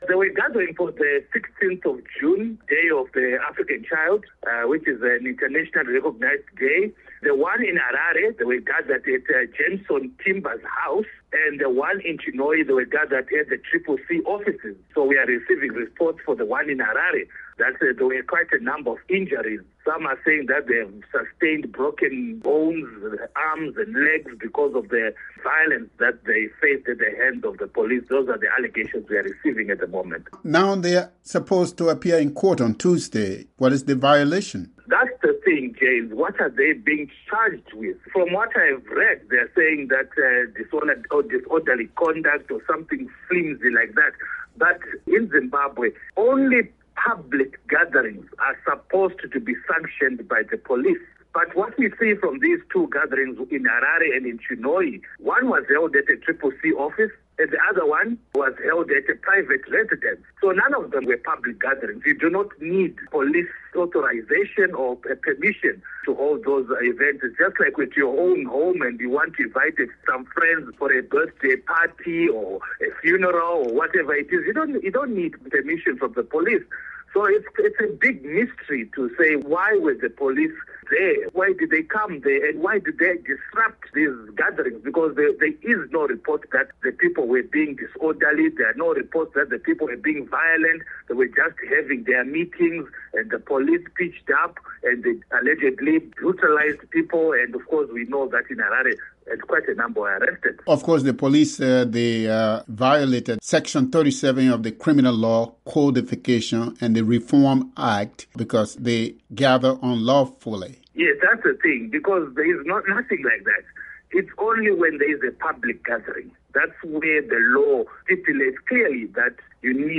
a Zimbabwe political analyst